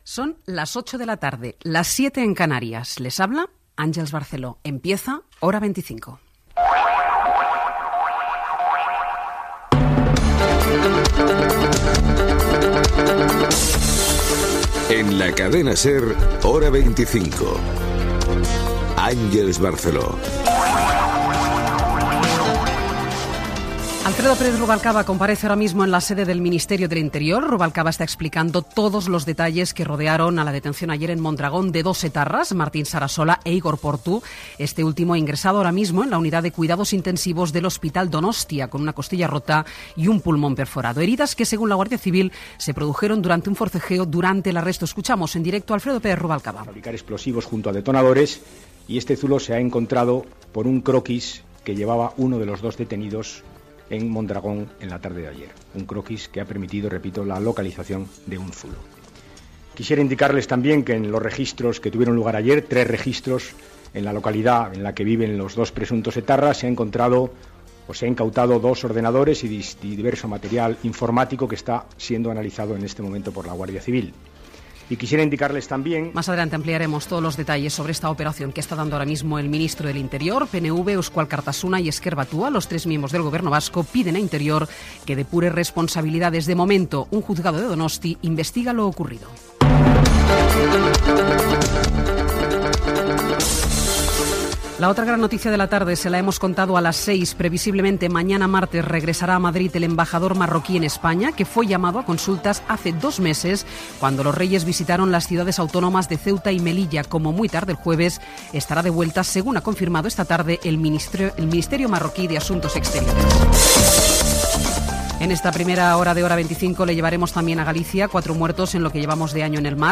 Hora, careta del programa, detenció de membres de la banda ETA amb declaracions del ministre Alfredo Pérez Rubalcaba, Marroc, naufragi a Galícia
Informatiu
Primera edició del programa presetada per Àngels Barceló.